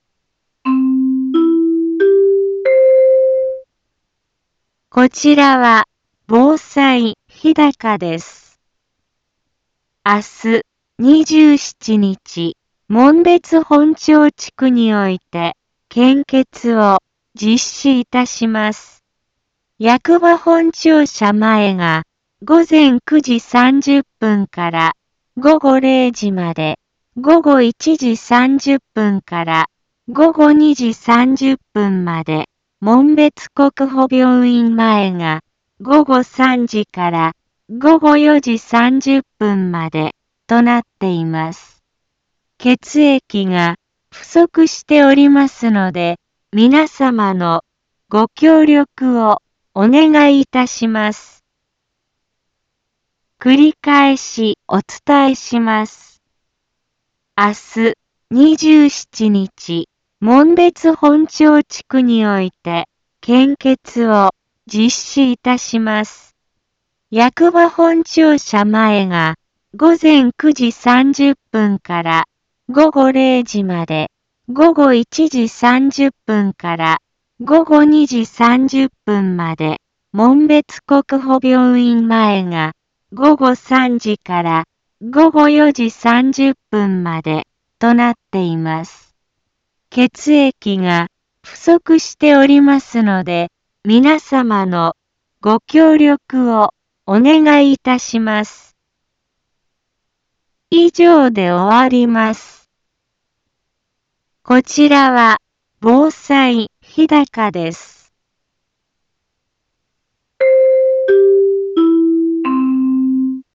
一般放送情報
Back Home 一般放送情報 音声放送 再生 一般放送情報 登録日時：2022-05-26 15:03:59 タイトル：献血広報 インフォメーション：こちらは防災日高です。